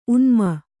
♪ unmana